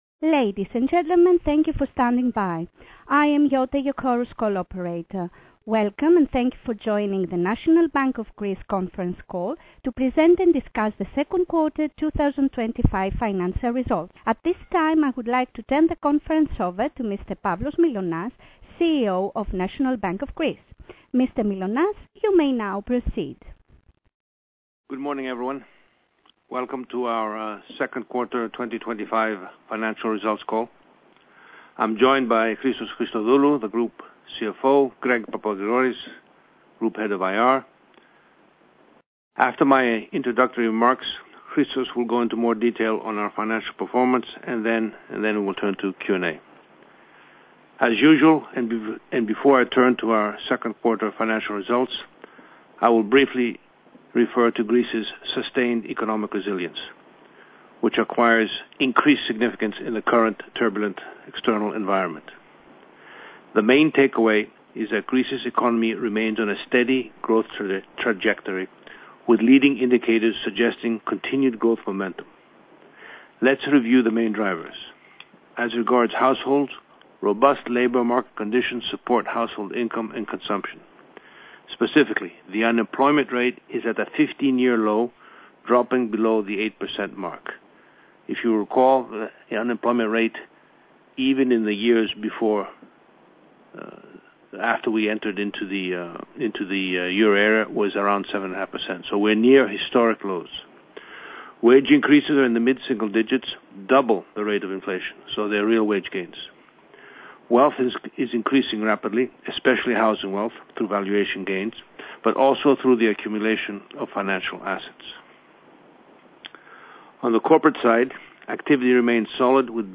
Τηλεδιάσκεψη B' Τριμήνου 2025 (Διαθέσιμο μόνο στα Αγγλικά)